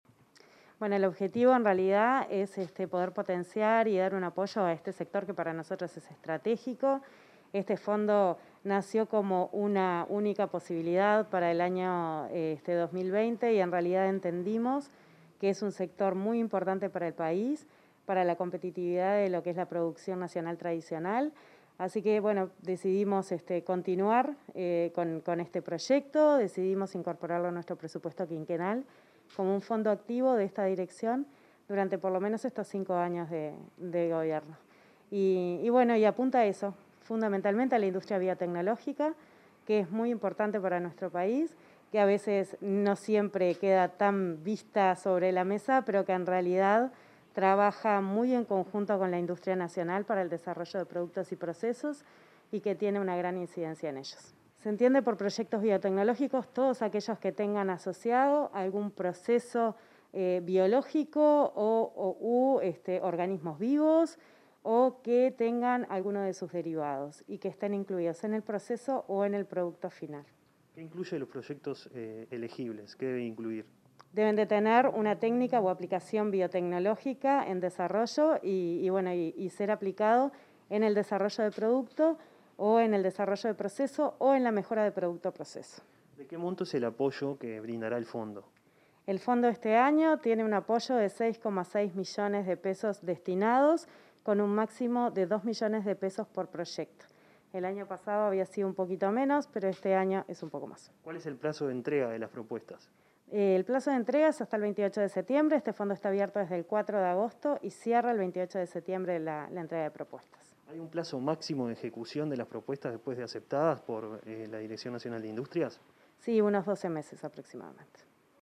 Entrevista a la directora nacional de Industrias, Susana Pecoy
Este martes 24, la directora nacional de Industrias del Ministerio de Industria y Energía, Susana Pecoy, dialogó con Comunicación Presidencial sobre